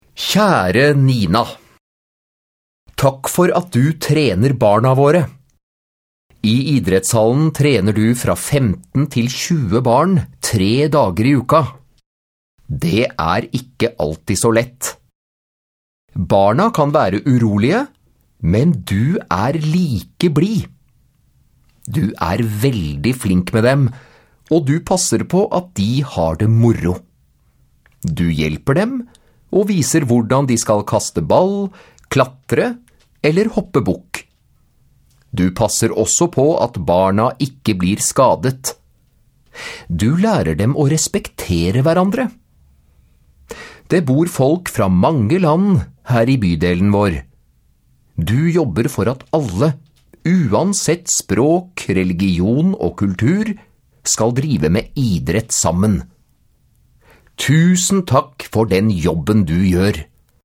Tale Jeg kan holde en tale.